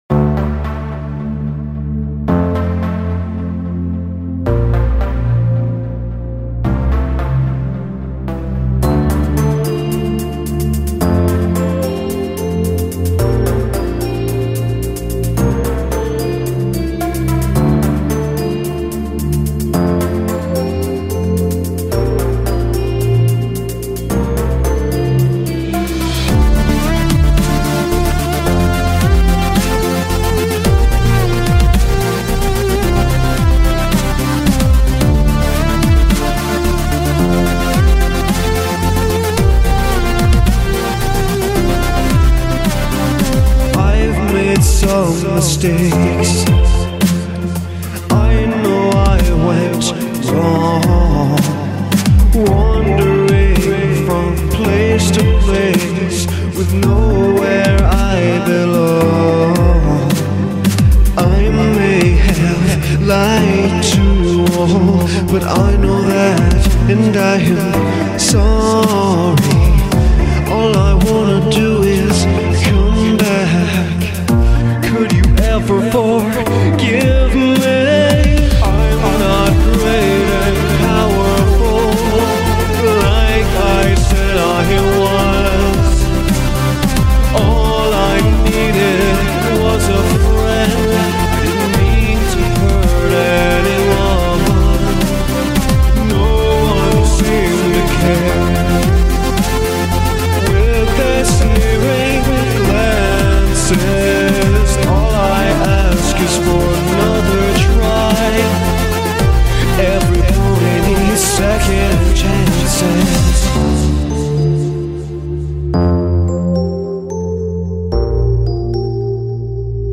I got emotional writing this, it's sad.